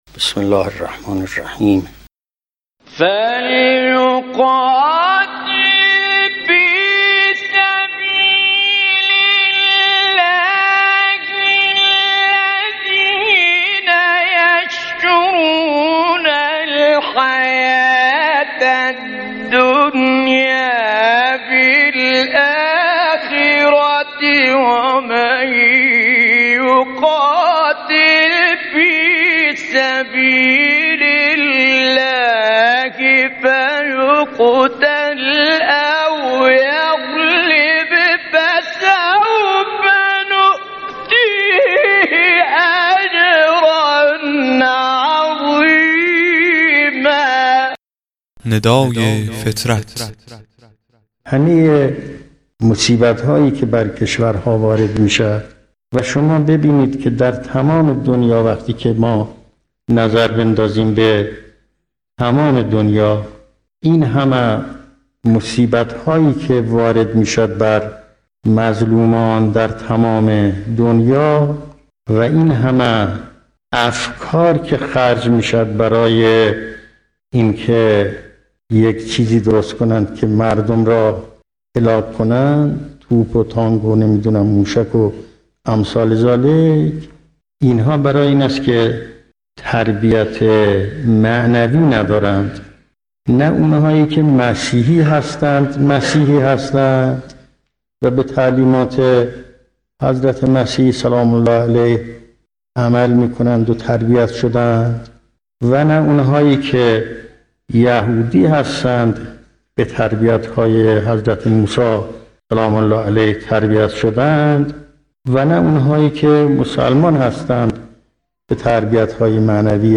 قطعه صوتی کوتاه و زیبا از امام خمینی(ره)